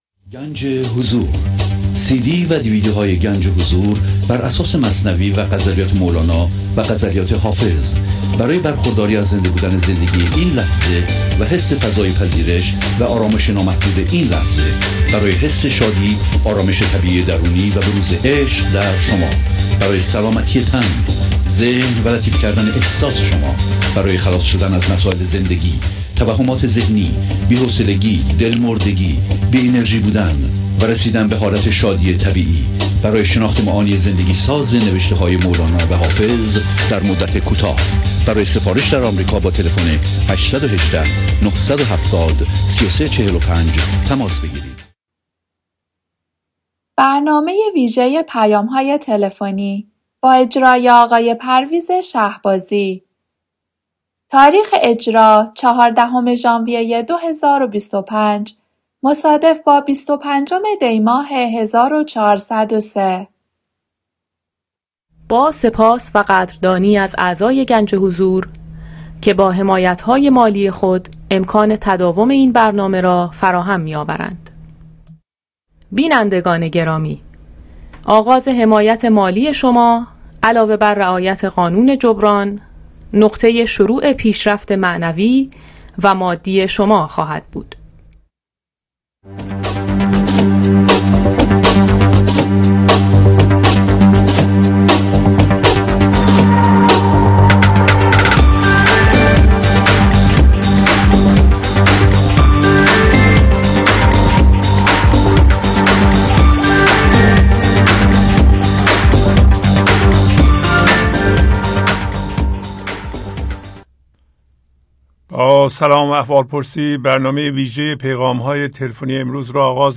Phone Calls Audio Programs #1021-2.